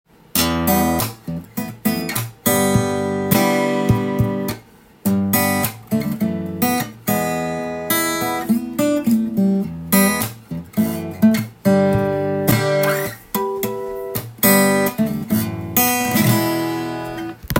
試しに弾いてみました
指で弾いて驚いたのが、現代の音がするのかと思いきや
古風な昭和サウンドがしました。
ボディーが小さい分　高音が強調されるので
昔ながらのギターという感じでした。